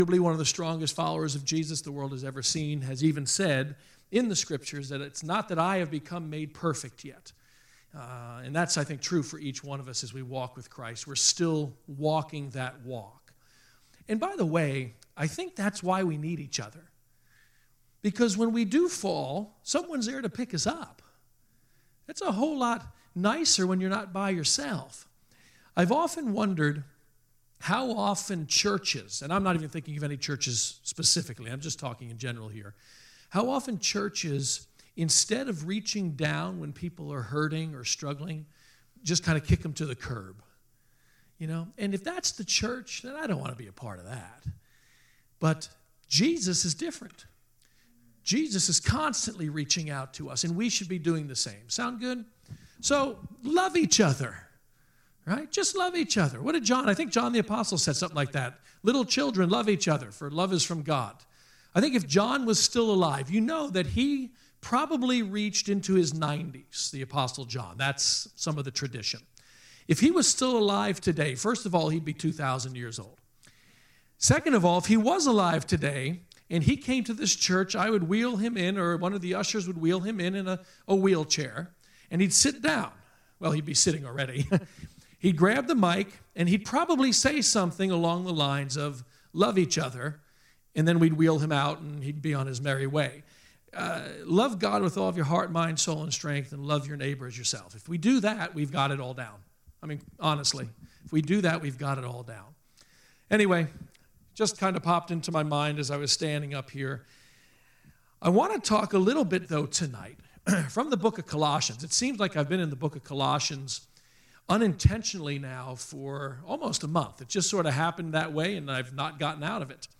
sermon series hebrews